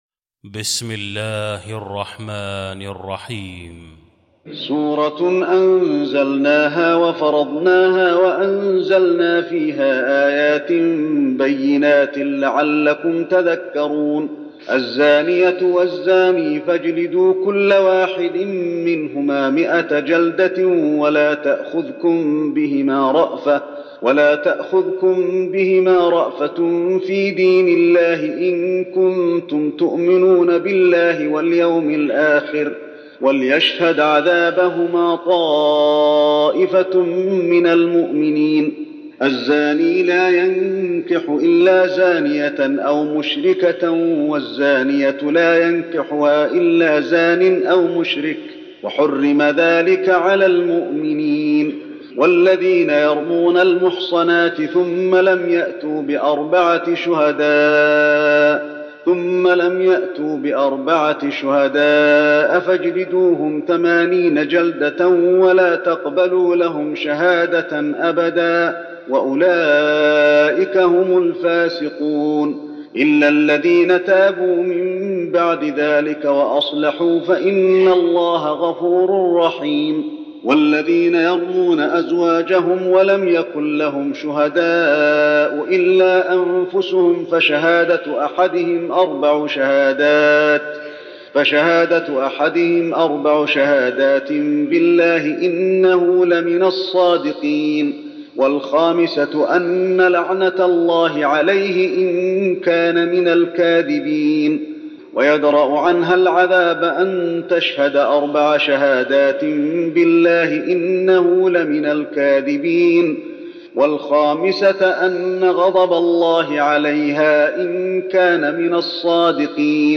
المكان: المسجد النبوي النور